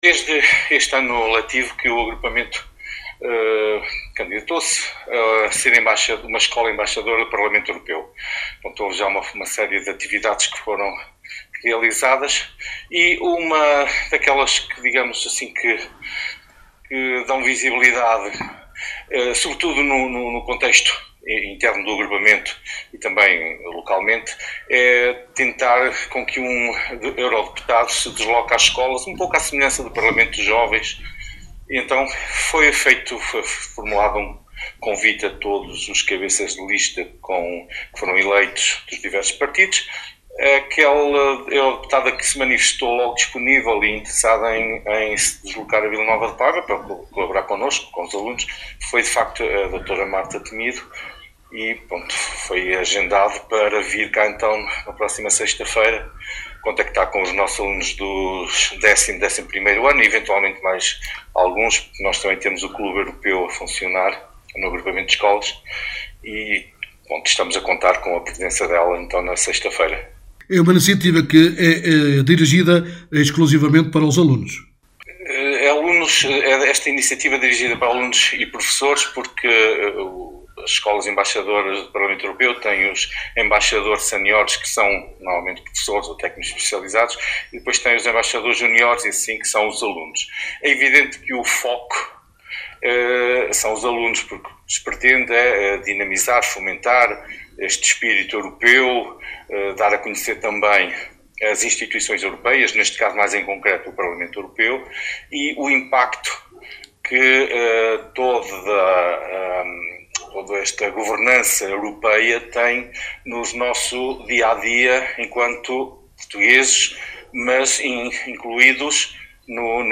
em declarações à Alive FM fala desta iniciativa.